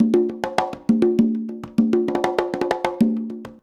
133CONGA06-L.wav